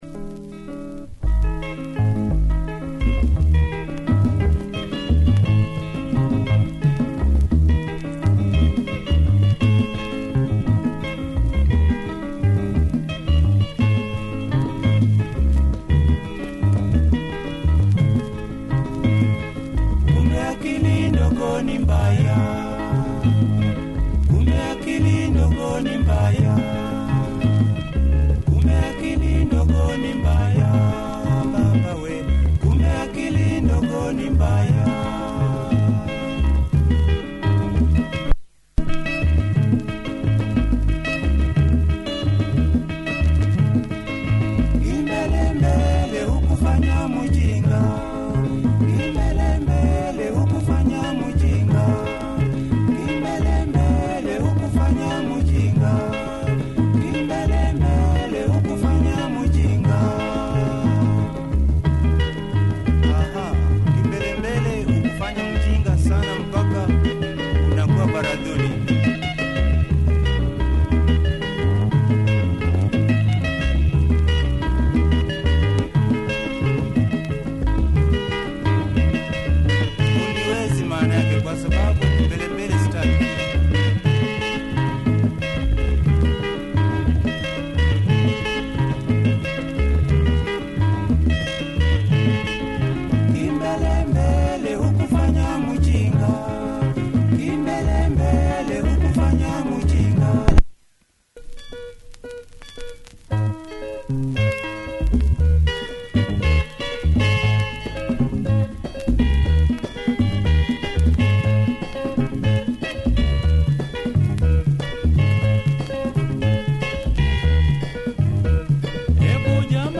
Sweet rumba vibe
good percussion and rumbling bass on the B-side